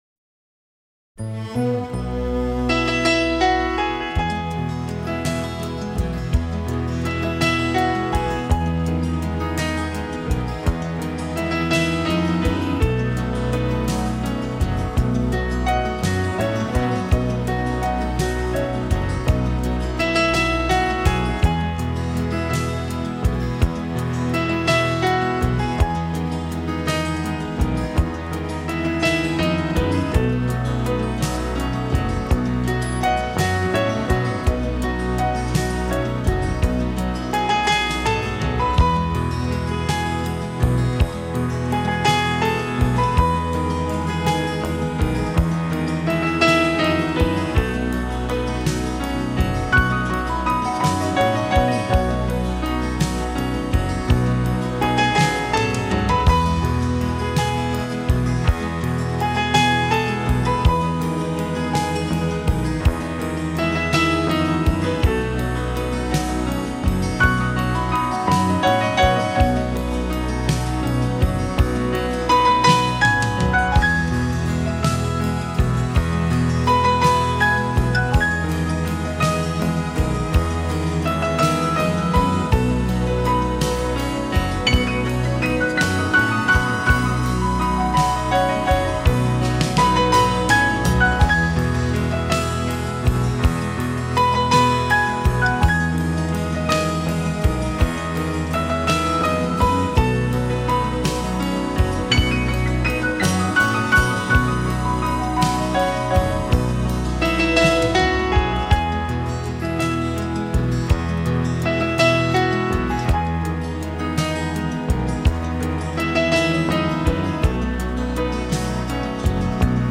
钢琴与长笛的对话 MP3-320K